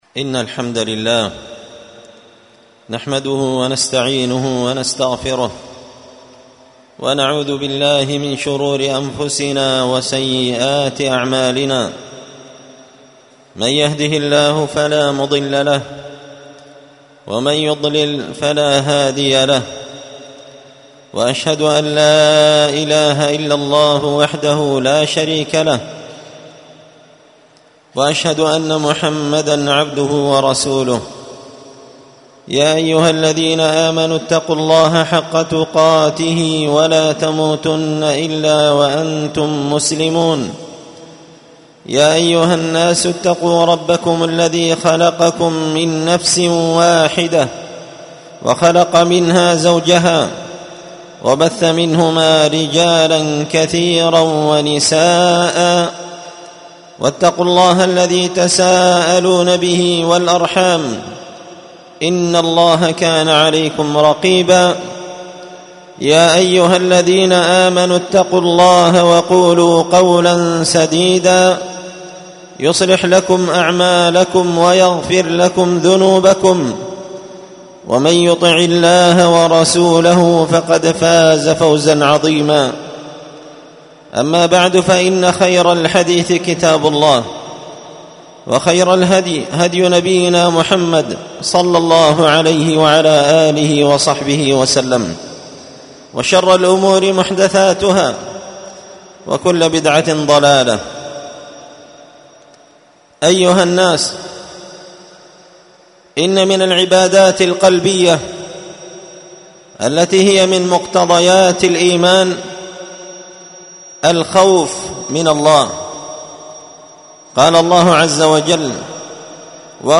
خطبة جمعة بعنوان: